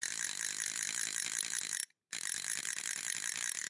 spinStart.0b14d15c.mp3